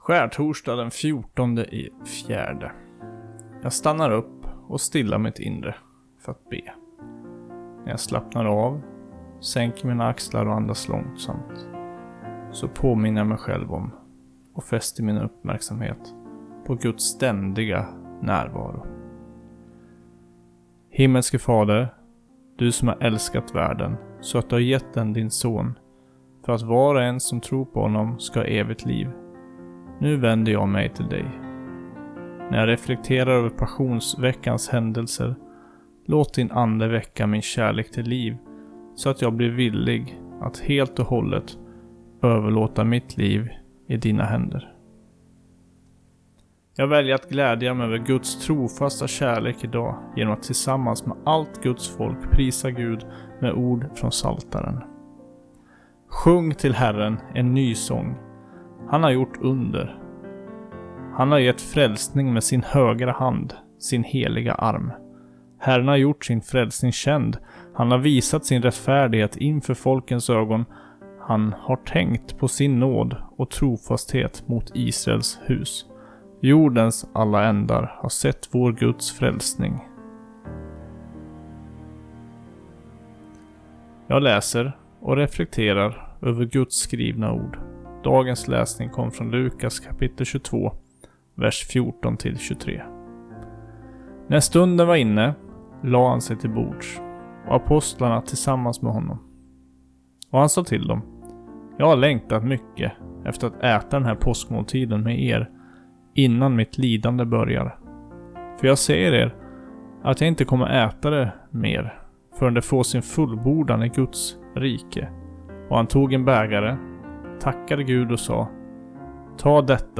Lyssna på andakterna